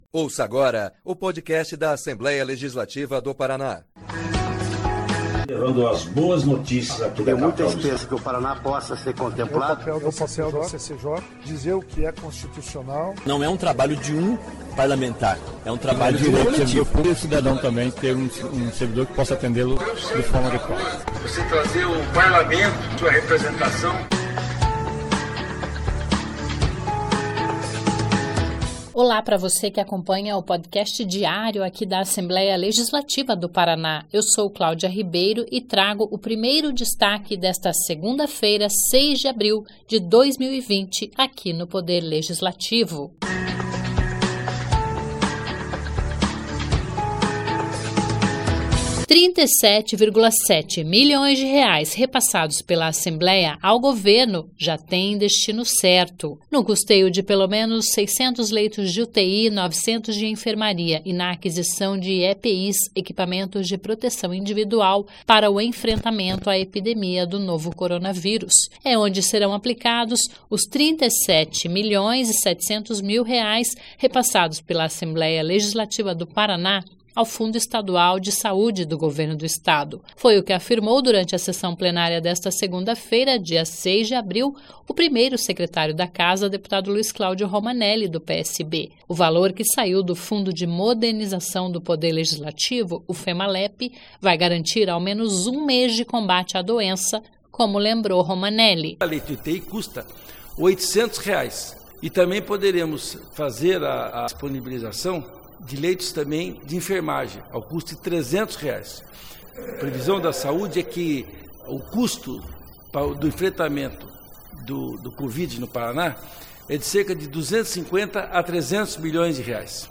Foi o que afirmou, durante a sessão plenária desta segunda-feira (6), o 1º secretário da Casa, deputado Luis Claudio Romanelli (PSB).
O presidente da Assembleia, deputado Ademar Traiano (PSDB), ressaltou que é papel institucional do Poder Legislativo contribuir para o bem-estar do povo do Paraná.